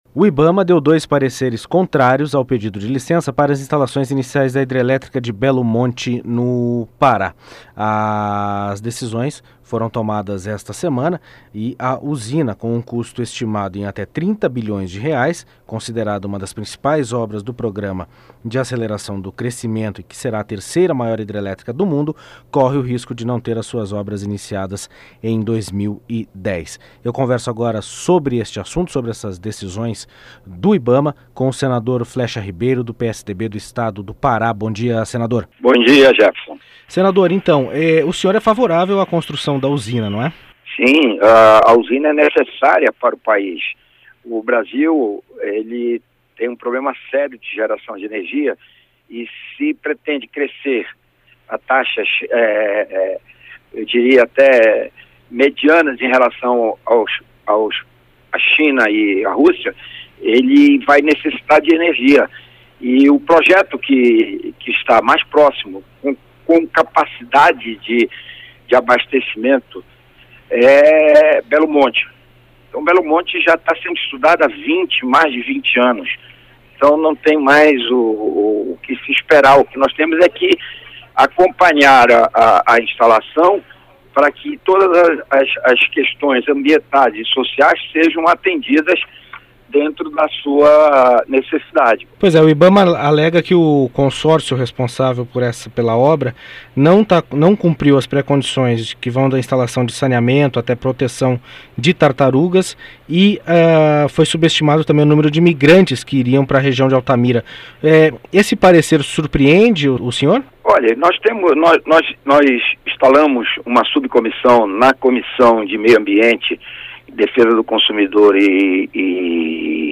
Entrevista com o senador Flexa Ribeiro (PSDB-PA).